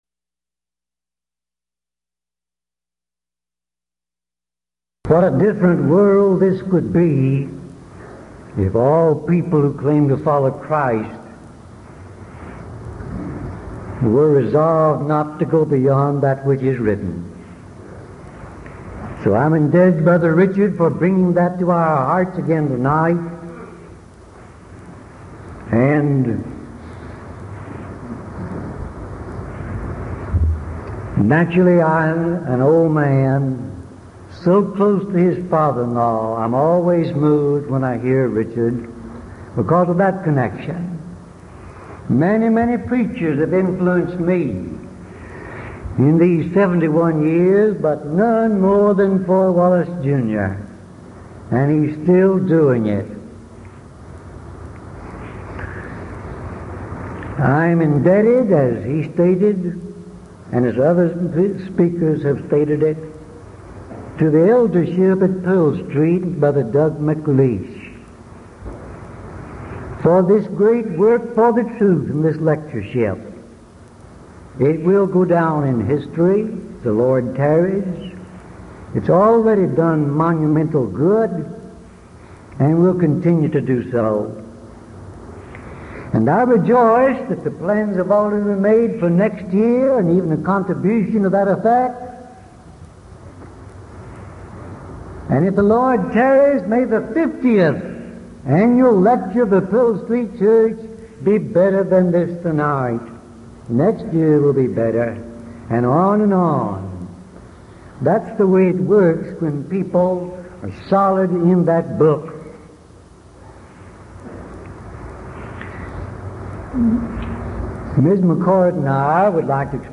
Event: 1982 Denton Lectures Theme/Title: Studies in 1 Corinthians